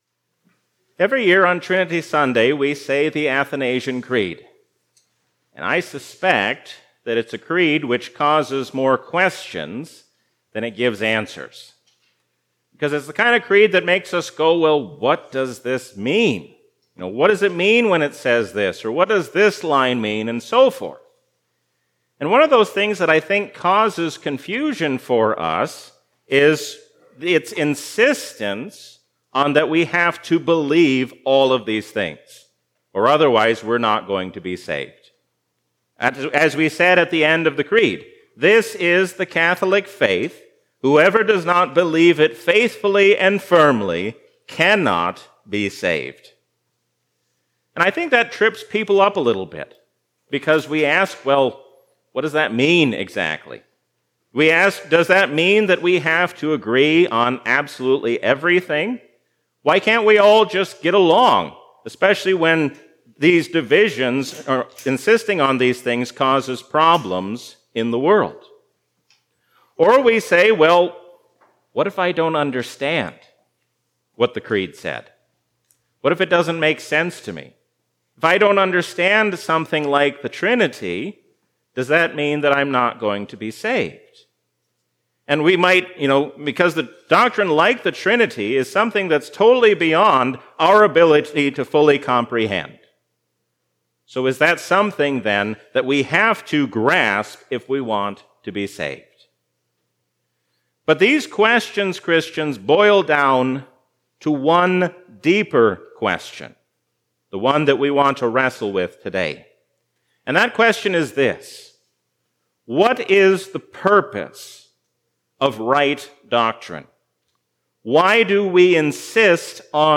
A sermon from the season "Trinity 2022." Preach the Word as good soldiers of Christ, like those who have gone before you.